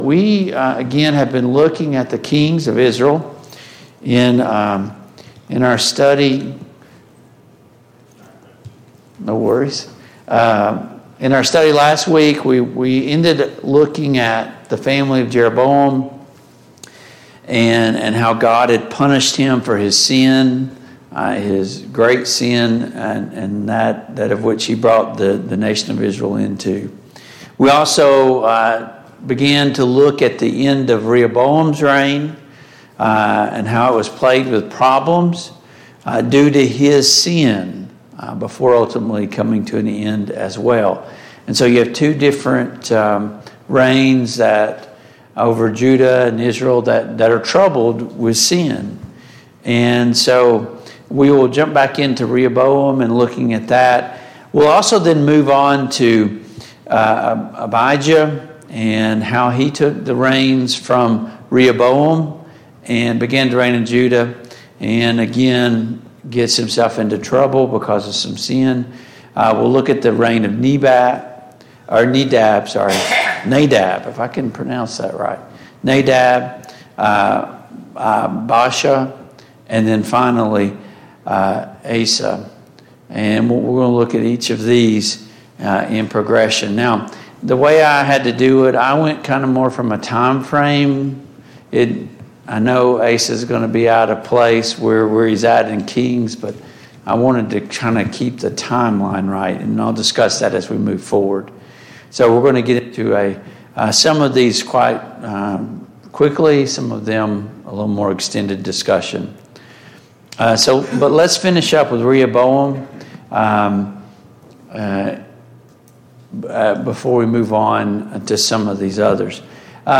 2 Chronicles 13 Service Type: Mid-Week Bible Study Download Files Notes « 5.